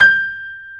G5-PNO93L -L.wav